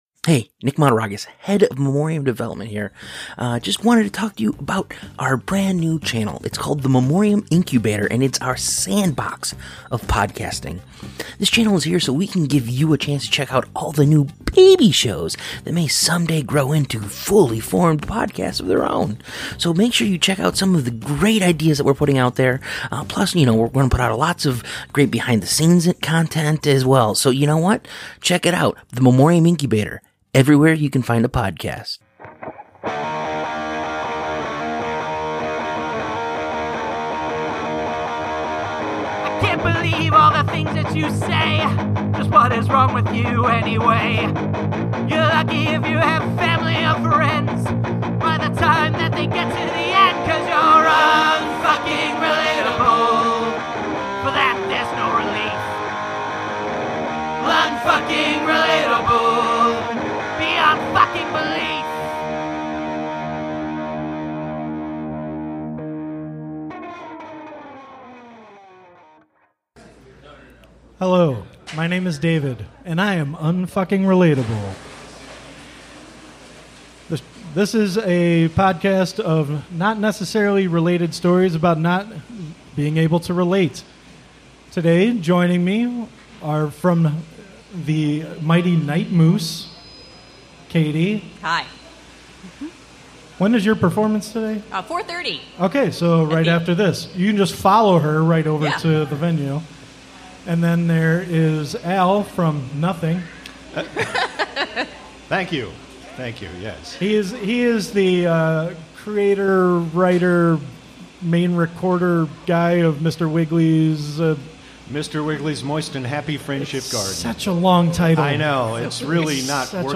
Live from the Blue Box Cafe, the second show from Elgin Fringe Festival was a change of pace. The original game Fumbling Dice was played for the first time on this back door pilot episode.